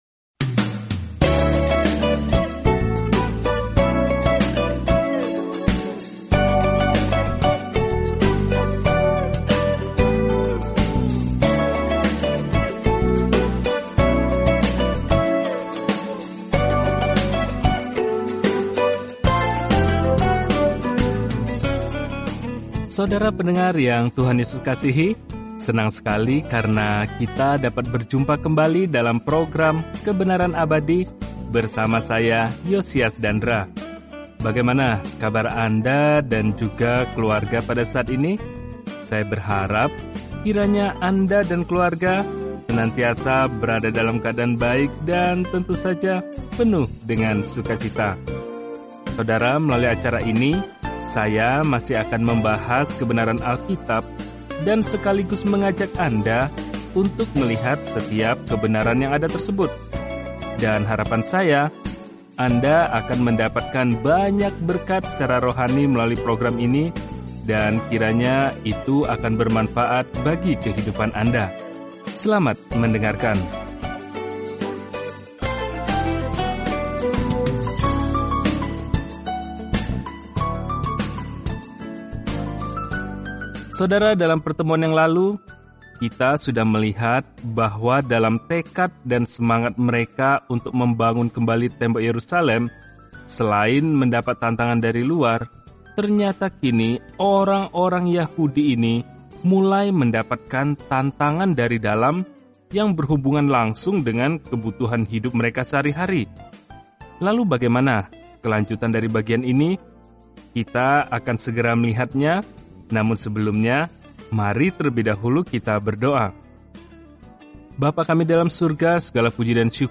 Firman Tuhan, Alkitab Nehemia 6 Hari 7 Mulai Rencana ini Hari 9 Tentang Rencana ini Ketika Israel kembali ke tanah mereka, kondisi Yerusalem buruk; seorang manusia biasa, Nehemia, membangun kembali tembok di sekeliling kota dalam buku Sejarah terakhir ini. Telusuri Nehemia setiap hari sambil mendengarkan pelajaran audio dan membaca ayat-ayat tertentu dari firman Tuhan.